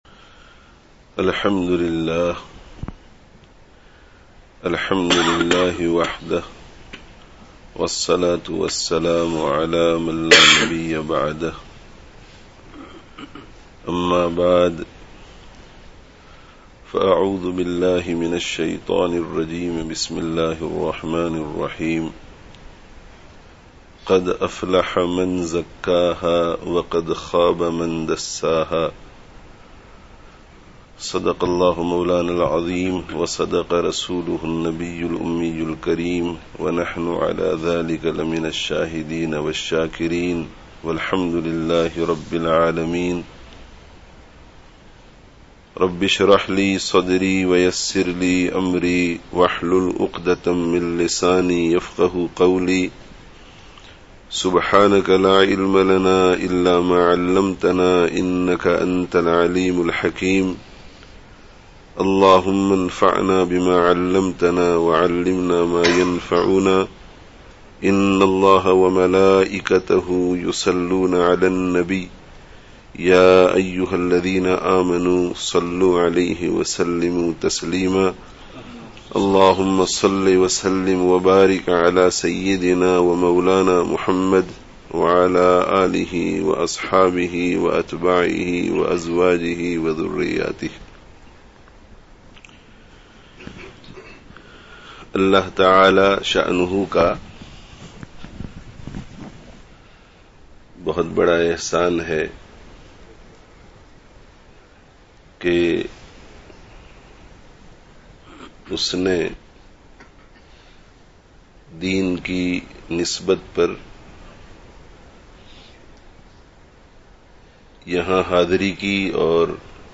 Kāmyābī kā Nuskhah (Masjid Mu'adh bin Jabal, Leicester 18/12/13)